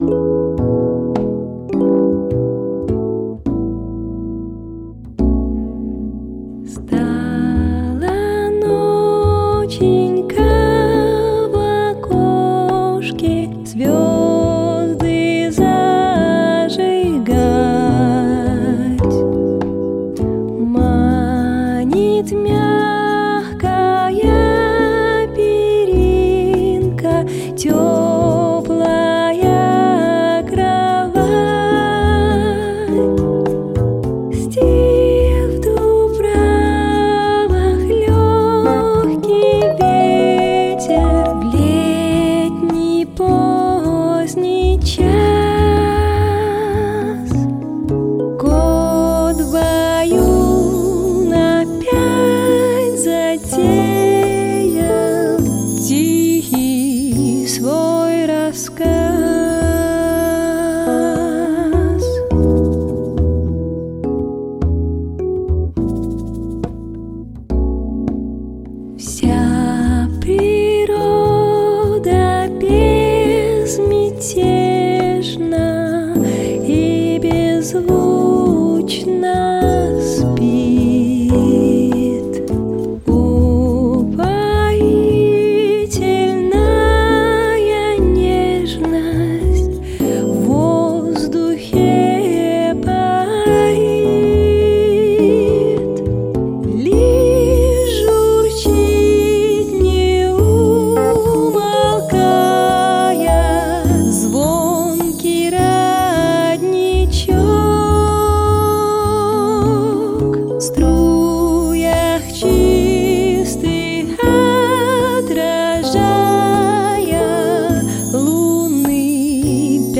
• Качество: Хорошее
• Жанр: Детские песни
колыбельная